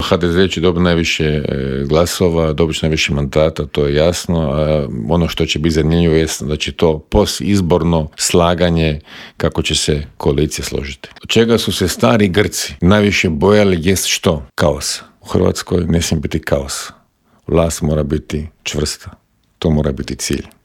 ZAGREB - Uoči druge godišnjice početka ruske agresije na Ukrajinu, u Intervjuu Media servisa razgovarali smo s bivšim ministrom vanjskih poslova Mirom Kovačem, koji nam je kratko proanalizirao trenutno stanje u Ukrajini, odgovorio na pitanje nazire li se kraj ratu, a osvrnuo se i na izbor novog glavnog tajnika NATO saveza i na nadolazeći sastanak Europskog vijeća.